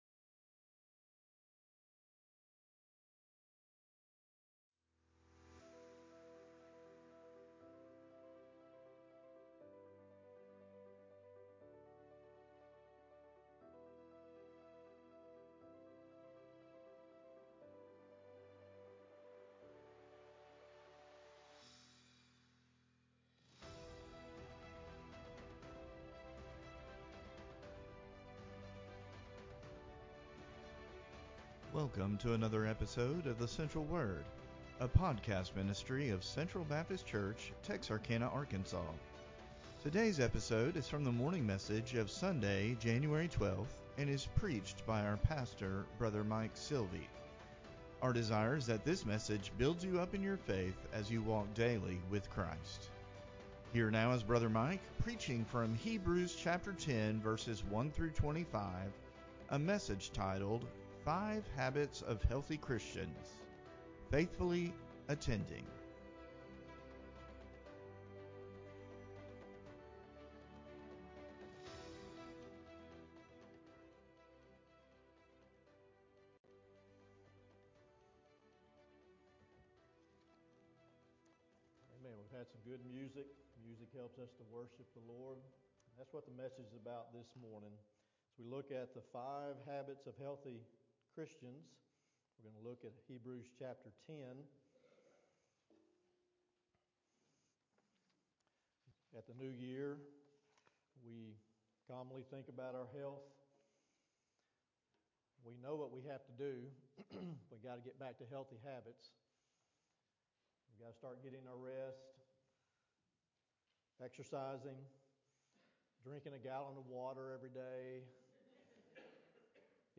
January12Sermon-CD.mp3